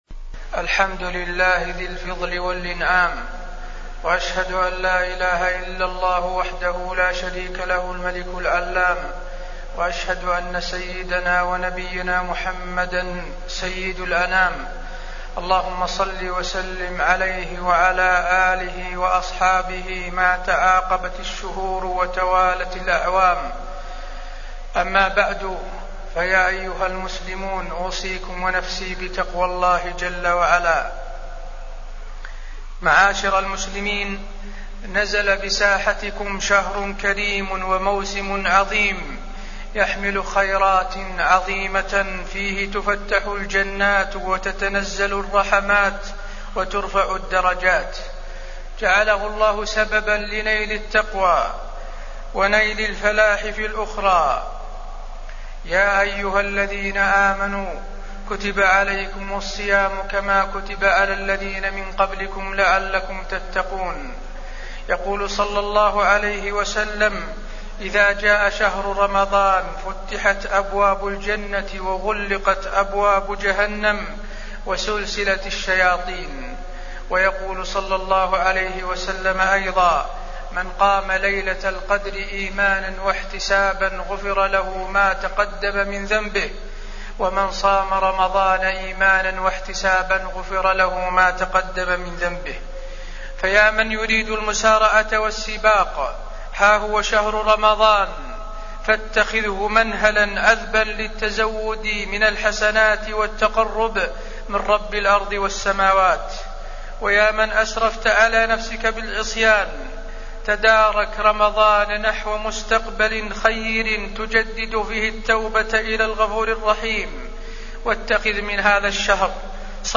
تاريخ النشر ٥ رمضان ١٤٢٩ هـ المكان: المسجد النبوي الشيخ: فضيلة الشيخ د. حسين بن عبدالعزيز آل الشيخ فضيلة الشيخ د. حسين بن عبدالعزيز آل الشيخ رمضان شهر الجود